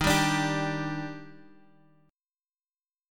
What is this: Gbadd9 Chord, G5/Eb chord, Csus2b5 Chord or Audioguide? G5/Eb chord